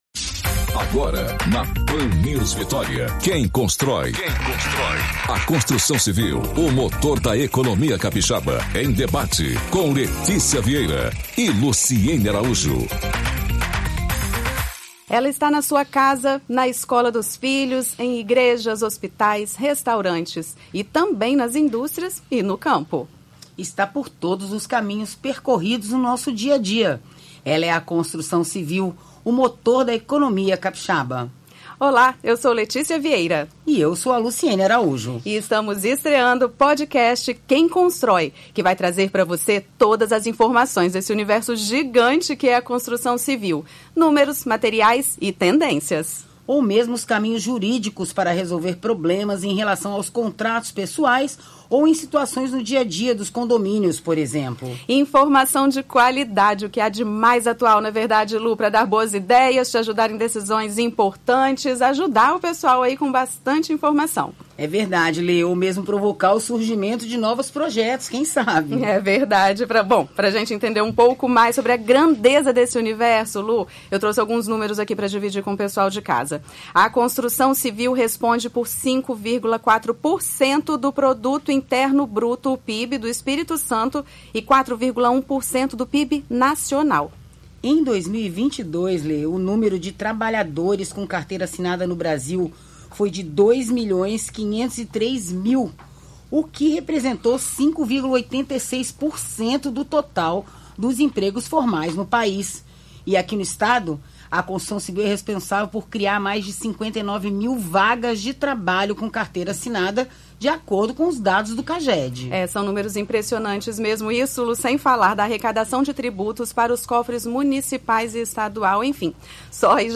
E esses problemas conhecidos como os 5 “Cs” dos condomínios foram o tema desta quarta-feira (07/06), no quadro Papo Com Especialista, do Programa Quem Constrói, da Pan News (90,5 FM).